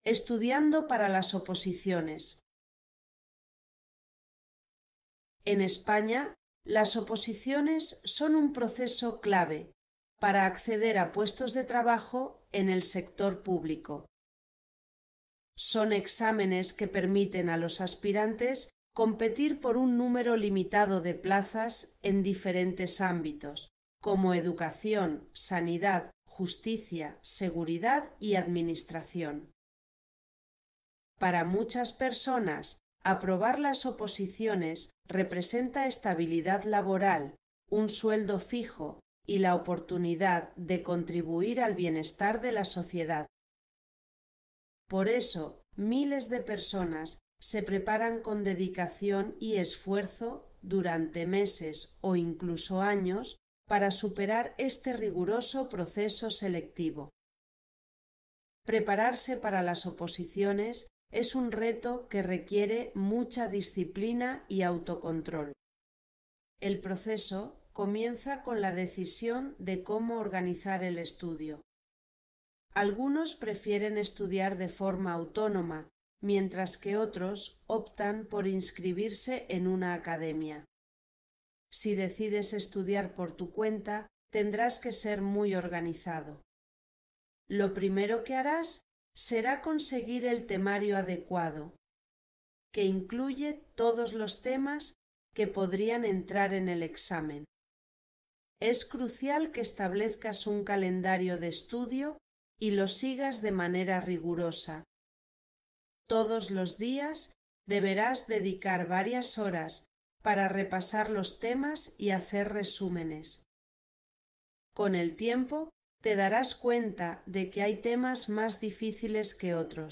Spanish online reading and listening practice – level B1